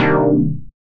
Synth Stab 18 (C).wav